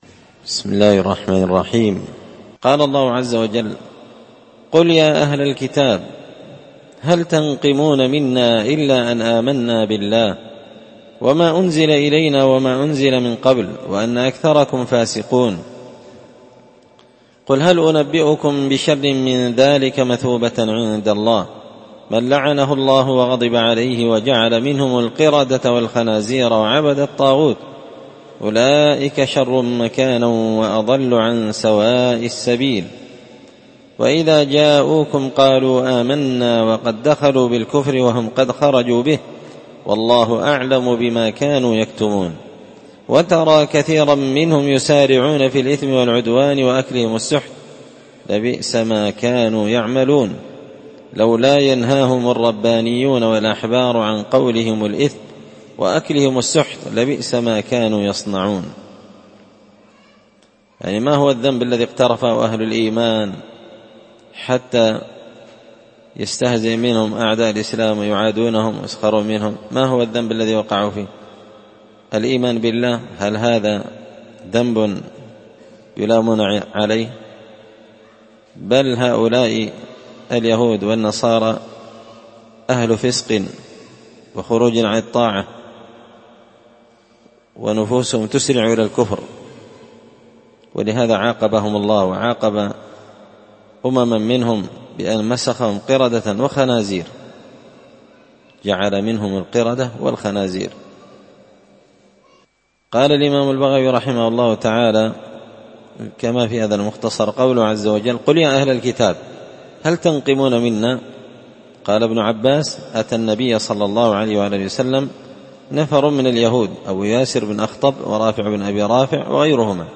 مختصر تفسير الإمام البغوي رحمه الله الدرس 265